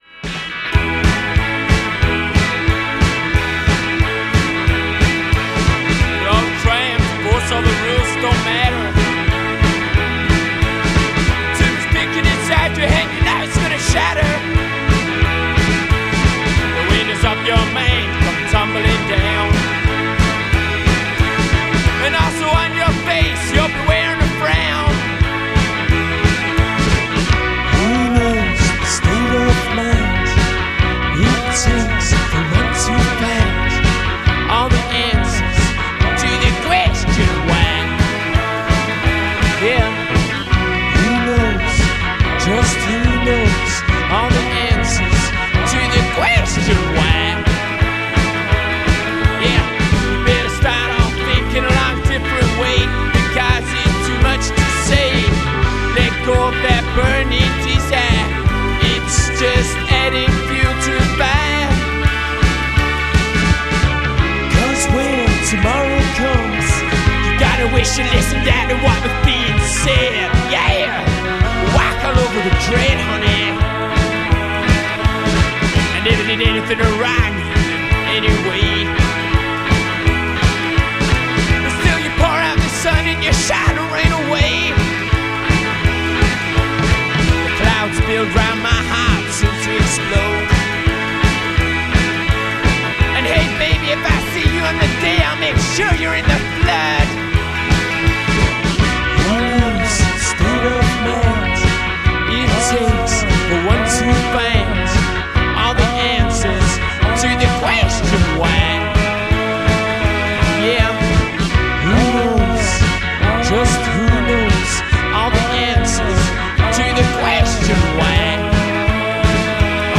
guitar and vocals
organ
drums